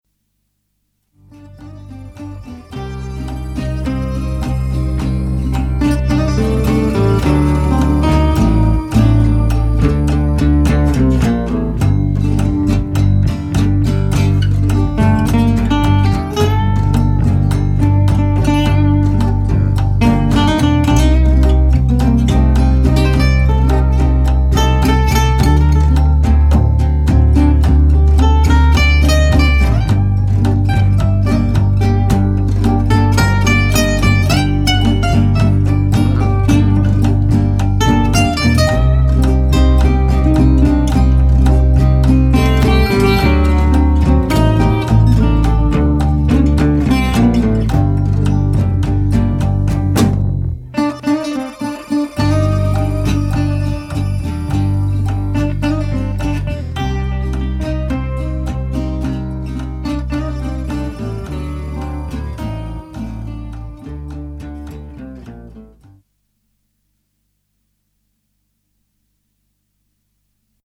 een funky jazzsfeer
klinkt een beetje als een soort van Zigeunermuziek